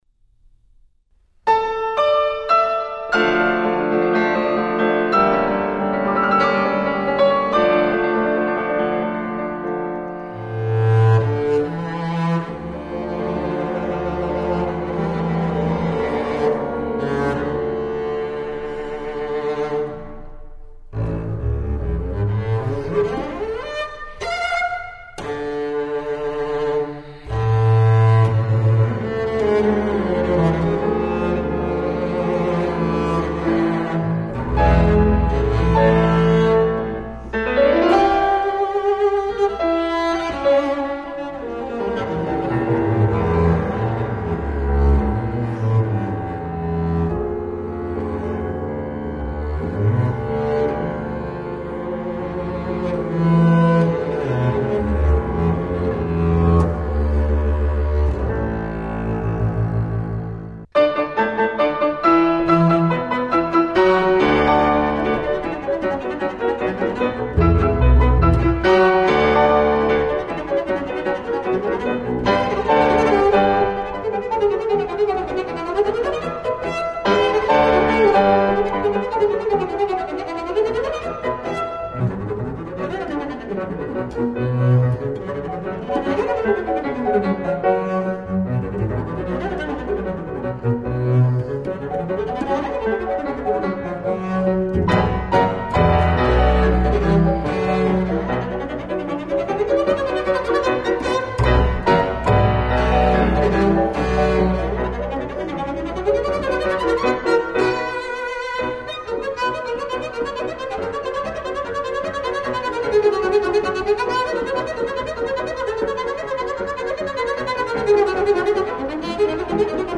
für Kontrabaß und kleines Streichorchester
eine ungewöhnliche Variante des virtuosen Werkes!